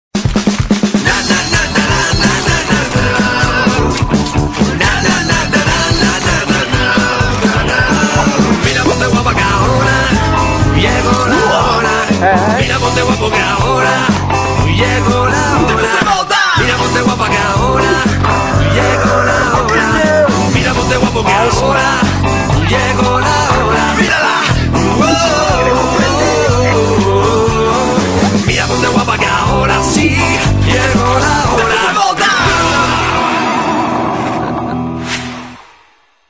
La sintonía del programa cómico